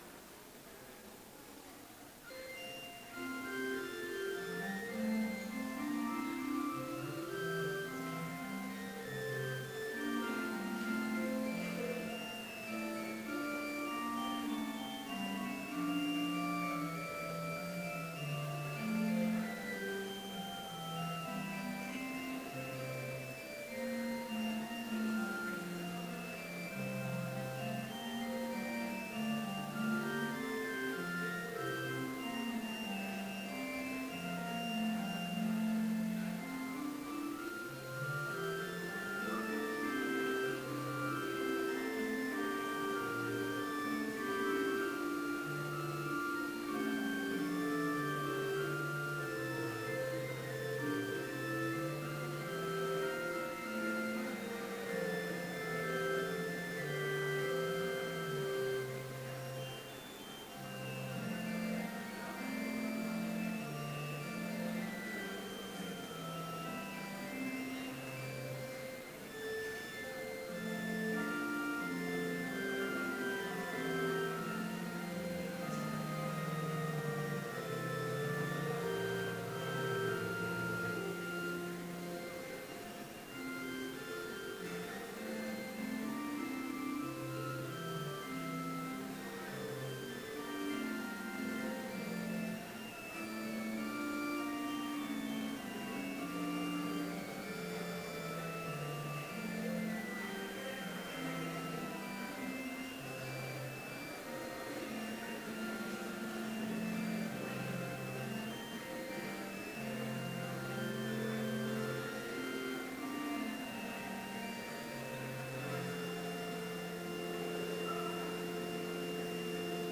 Complete service audio for Chapel - March 2, 2016
Prelude